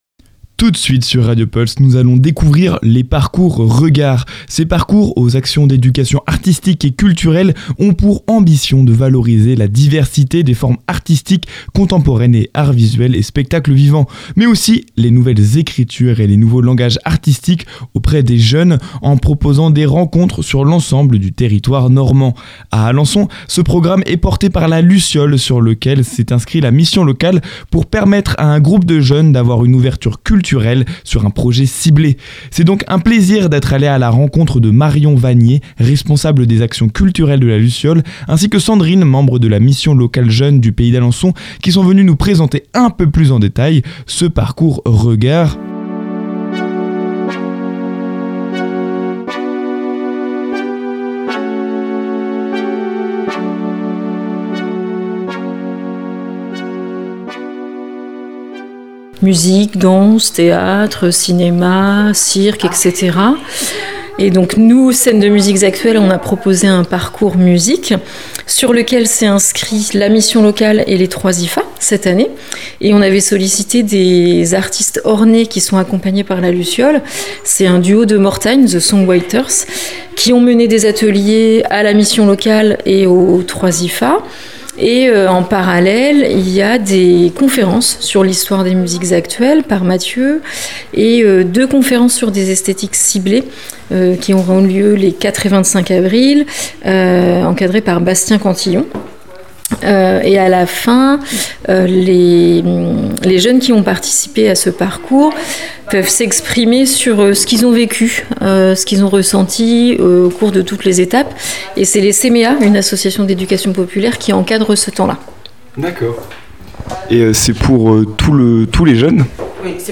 Lors de cette rencontre / interview